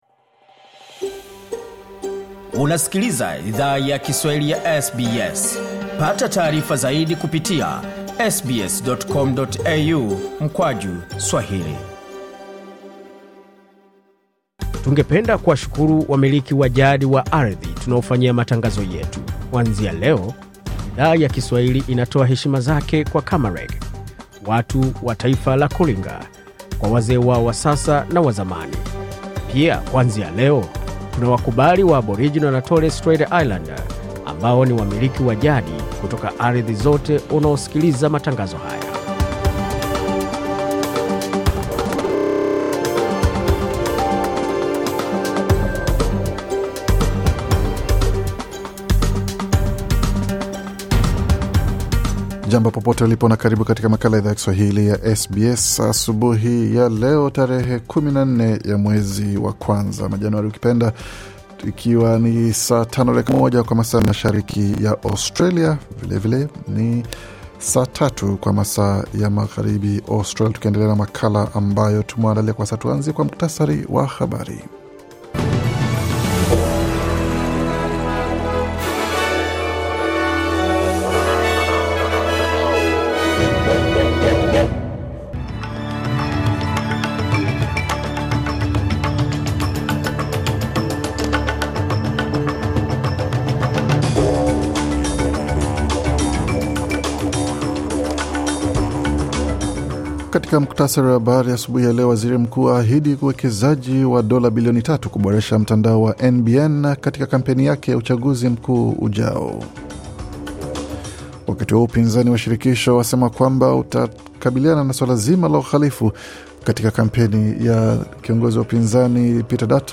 Taarifa ya Habari 14 Januari 2025